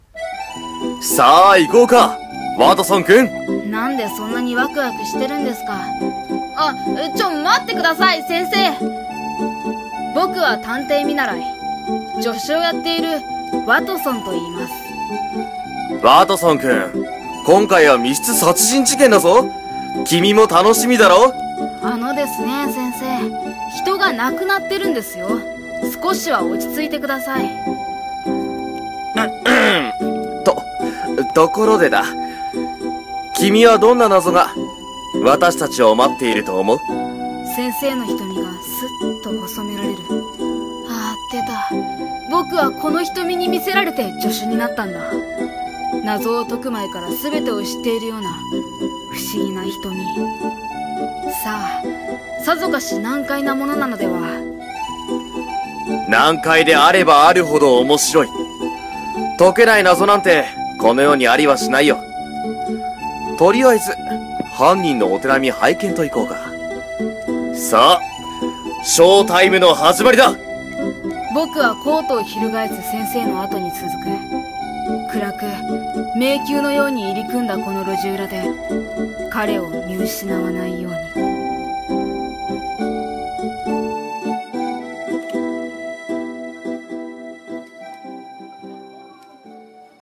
【声劇台本】街角の探偵。